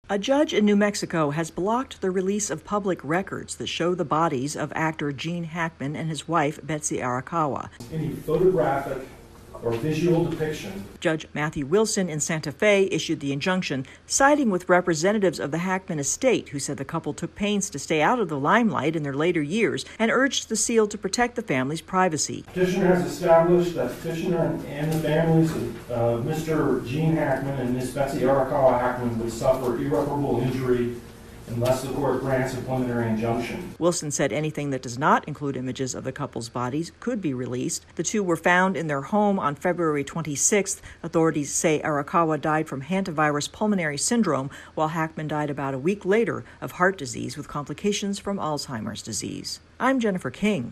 Images of the bodies from the investigation into the deaths of actor Gene Hackman and his wife will remain under seal for now. AP correspondent